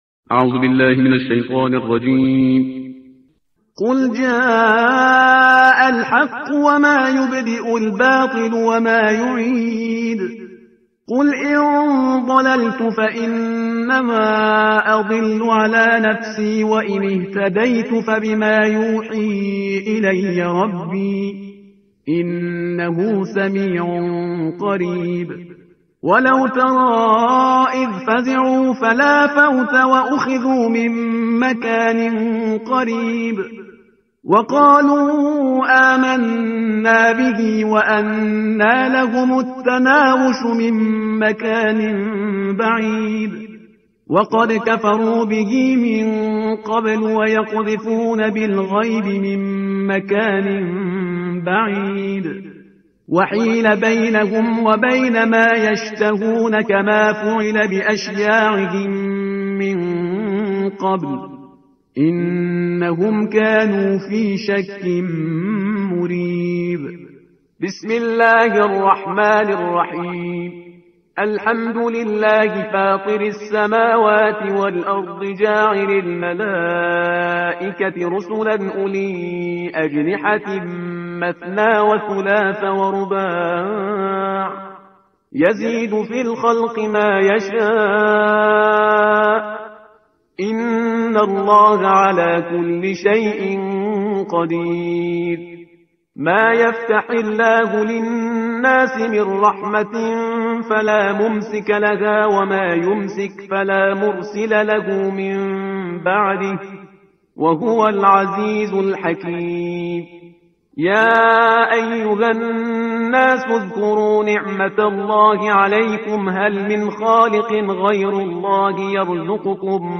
ترتیل صفحه 434 قرآن با صدای شهریار پرهیزگار